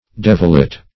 devilet - definition of devilet - synonyms, pronunciation, spelling from Free Dictionary Search Result for " devilet" : The Collaborative International Dictionary of English v.0.48: Devilet \Dev"il*et\, n. A little devil.